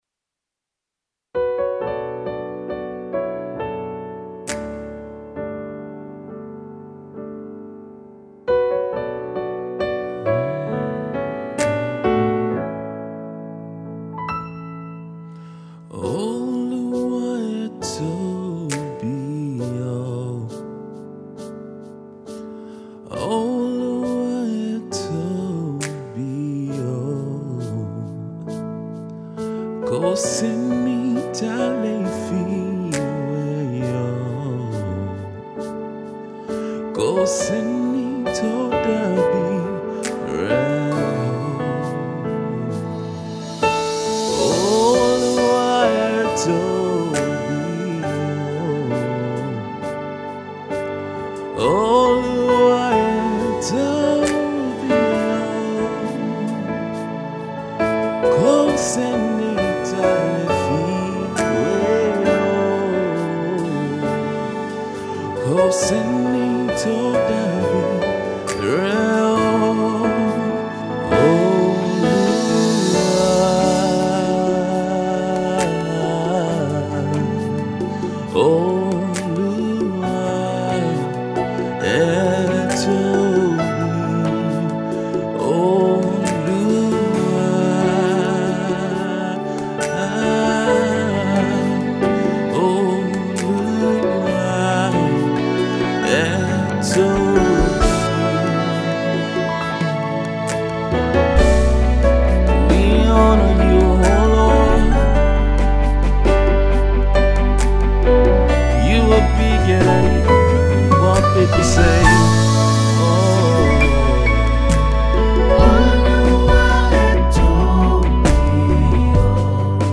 Gospel music singer